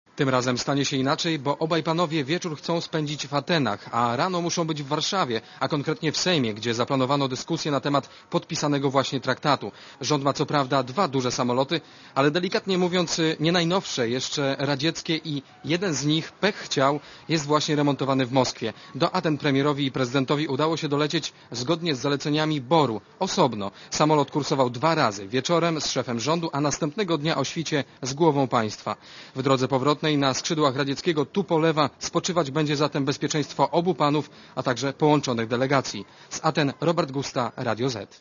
Posłuchaj relacji reportera Radia Zet z Aten (149 KB)